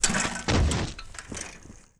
effect__bike_crash.wav